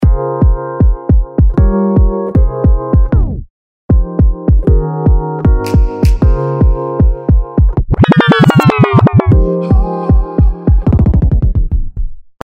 PITCH BREAKER」は、地味にとても使えるレコードストップ系のエフェクトで、BREAKでストップする強度、RATEでストップモーションの長さ、CURVEで滑り具合等、細かく調整ができます。
BACK SPIN」は文字通りバックスピン効果を、「ROLL OUT」はループしながら止まっていくような効果が得られます。